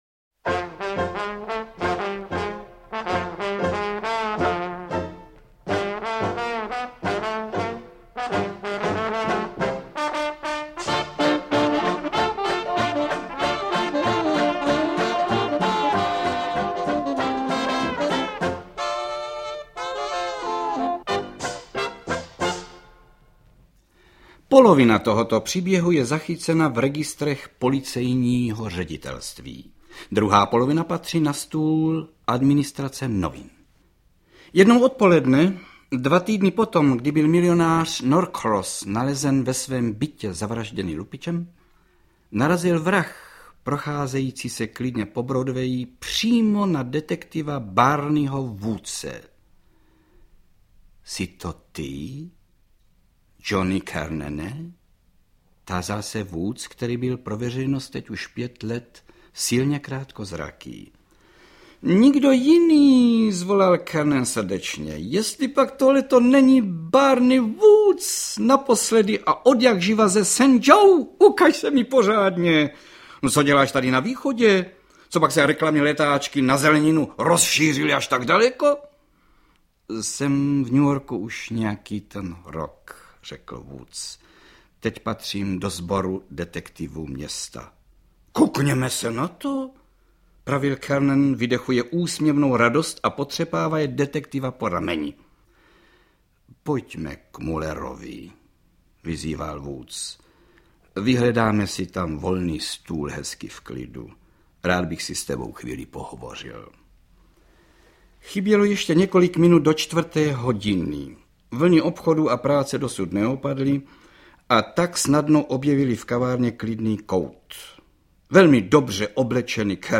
Interpret:  Karel Höger
AudioKniha ke stažení, 3 x mp3, délka 1 hod. 6 min., velikost 60,3 MB, česky